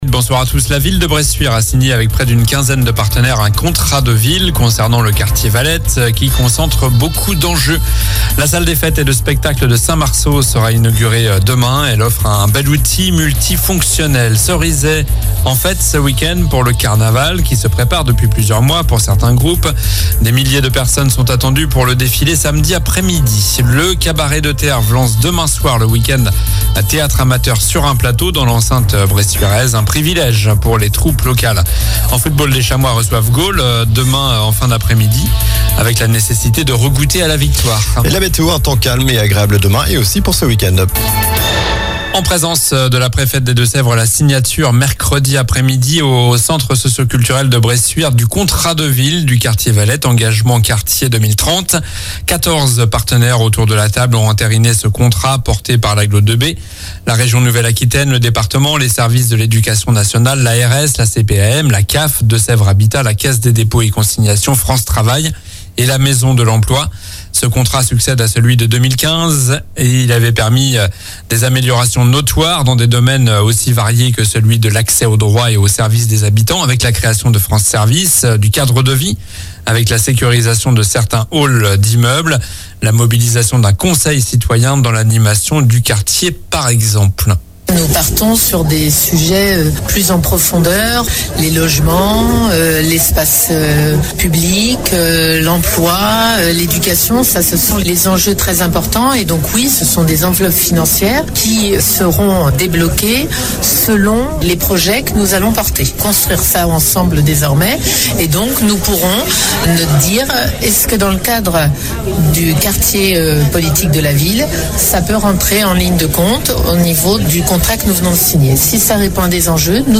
Journal du jeudi 11 avril (soir)